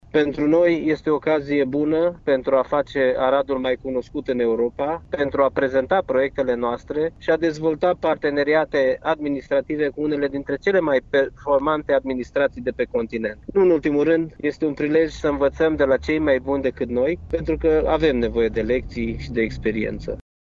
Diplomaţi şi şefi ai regiunilor din Europa se află zilele acestea la Arad, la Plenara de Primăvară a Adunării Regiunilor Europei
Reuniunea reprezentanţilor regiunilor europene de la Arad este cel mai important eveniment internaţional care are loc aici în ultimii ani, consideră preşedintele Consiliului Judeţean Arad, Iustin Cionca. Liderul CJ Arad spune că reuniunea este un bun prilej pentru a face cunoscut Aradul în Europa, pentru a prezenta proiectele sale și de a dezvolta parteneriate administrative.